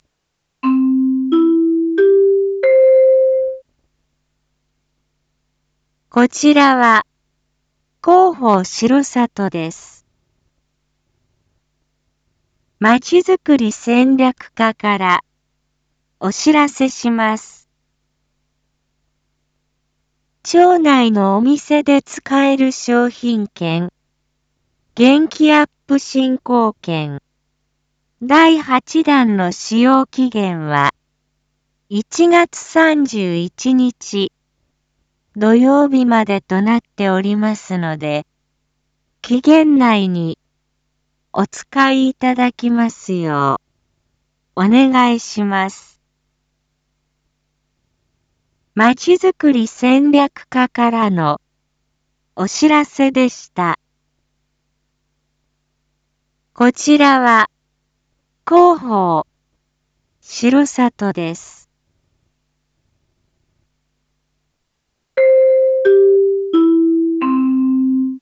Back Home 一般放送情報 音声放送 再生 一般放送情報 登録日時：2026-01-26 19:01:09 タイトル：元気アップ振興券（第８弾）使用期限 インフォメーション：こちらは、広報しろさとです。